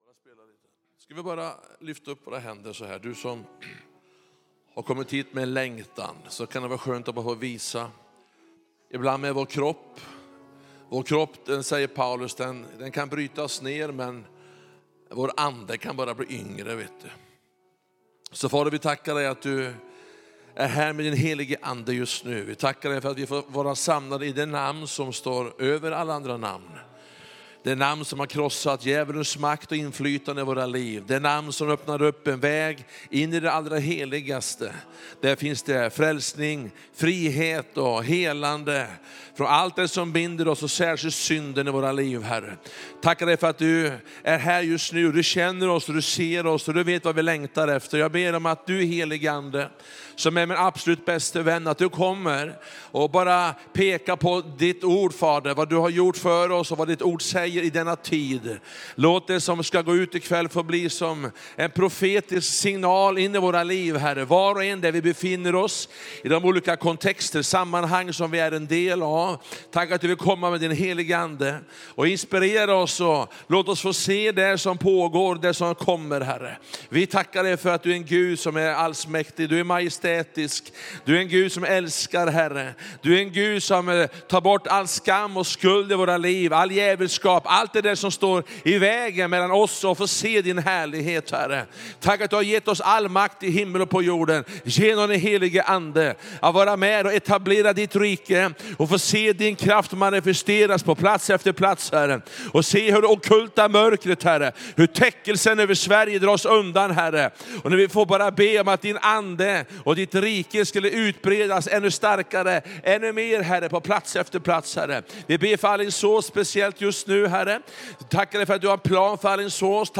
Undervisning och predikningar från Sveriges kyrkor.